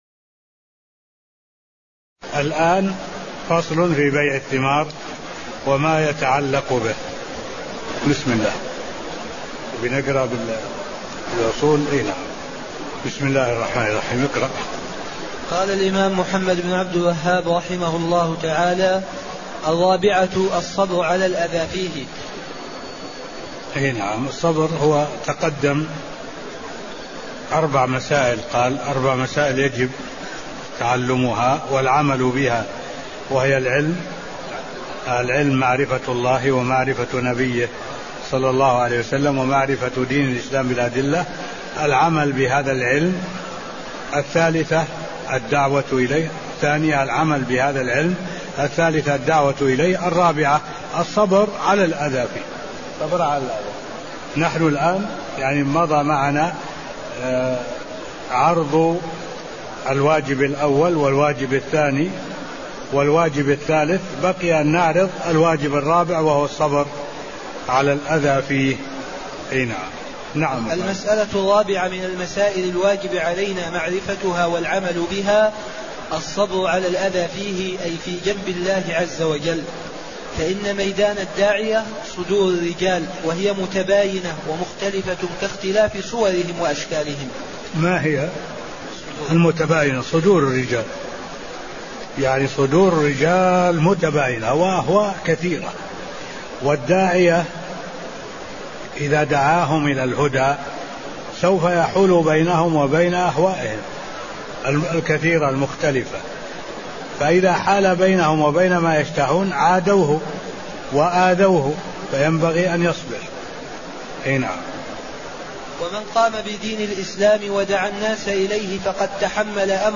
المكان: المسجد النبوي الشيخ: معالي الشيخ الدكتور صالح بن عبد الله العبود معالي الشيخ الدكتور صالح بن عبد الله العبود فصل قوله: (الرابعة الصبر على الأذى فيه) (02)